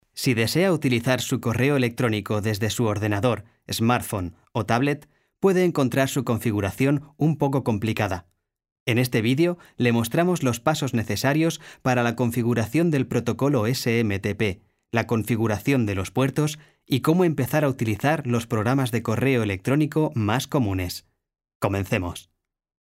Tengo una voz joven, educada y aplicable en multitud de estilos, como publicidad, documentales, audiolibros,... Especial habilidad para interpretar personajes con voz fuera de lo común. Amplia experiencia en "listenings" de cursos de español.
kastilisch
Sprechprobe: eLearning (Muttersprache):